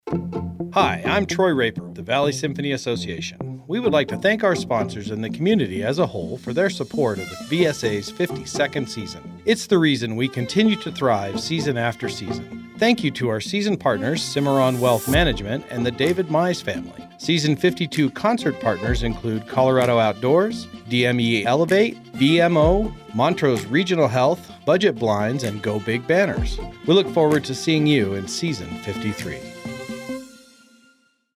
Radio Ad: Thank you for a great 52nd season